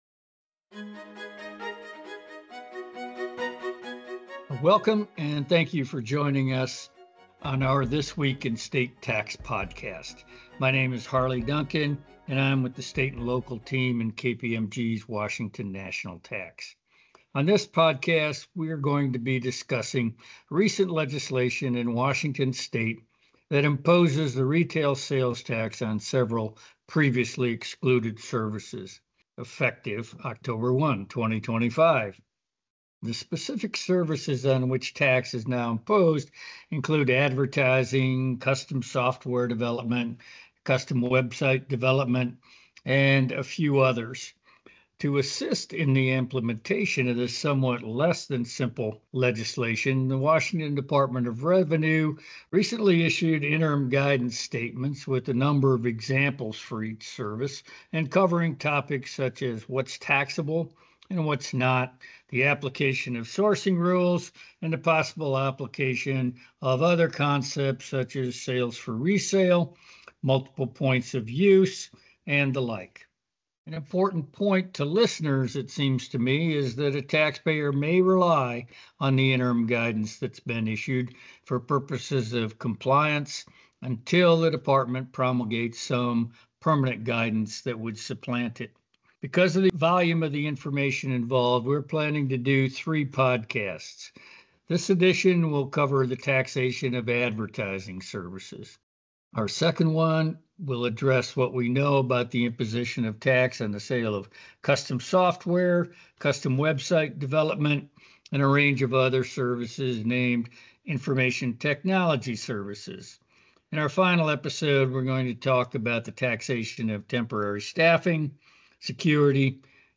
Washington's new digital ad tax. On this TWIST podcast WNT SALT professionals break down what's taxable and how to stay compliant.